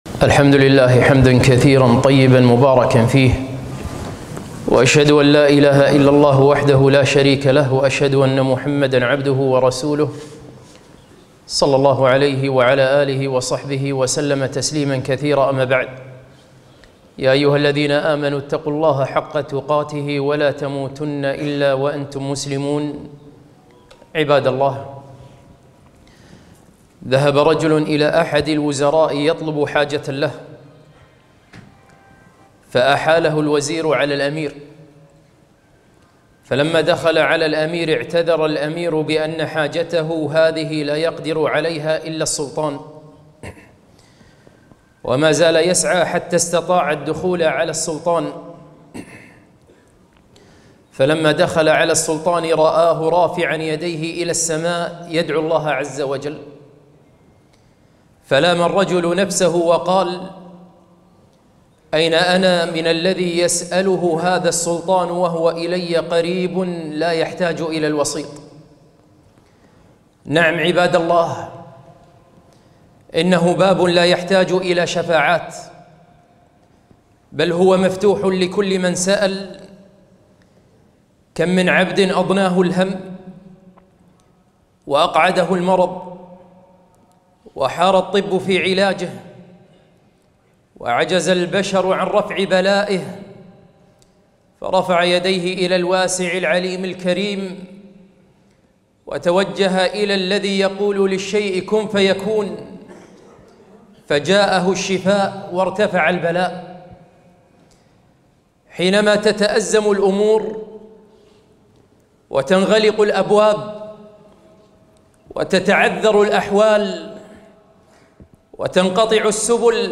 خطبة - الدعاء وتفريج الكروب